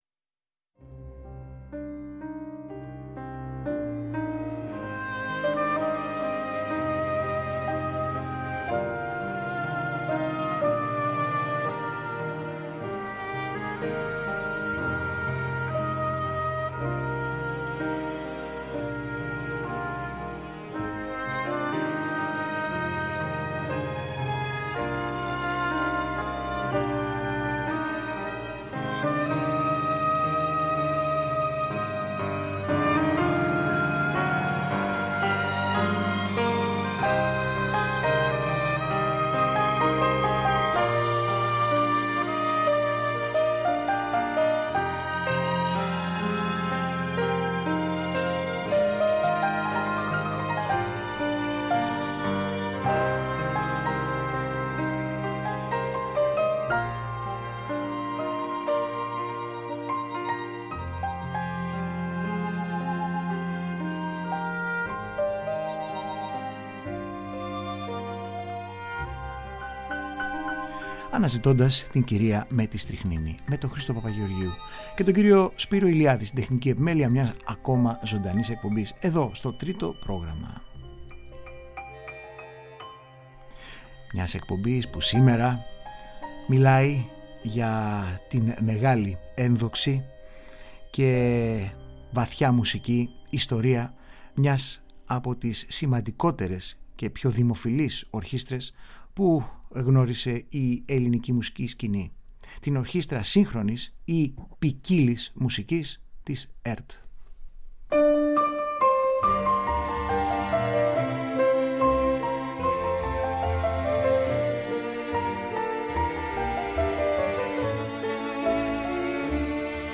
ζωντανά στο στούντιο του Τρίτου προγράμματος